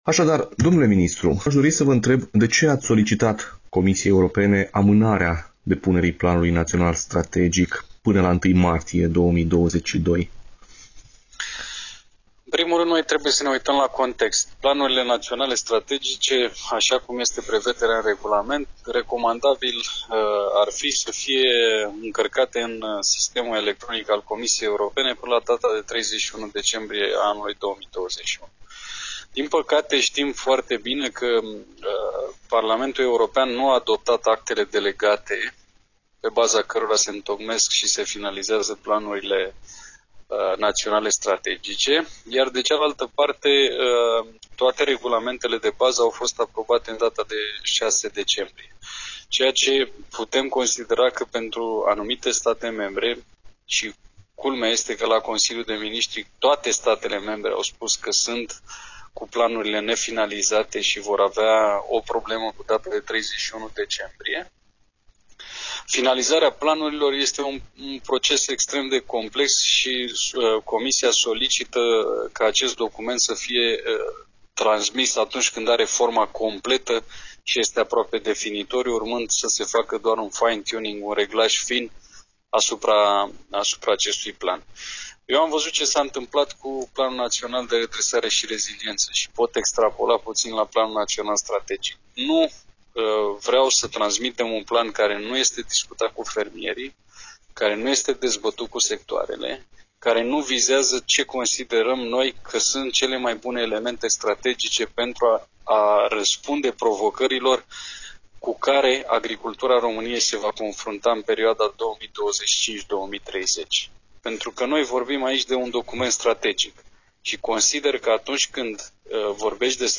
Interviu-Chesnoiu.mp3